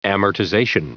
Prononciation du mot amortization en anglais (fichier audio)
Prononciation du mot : amortization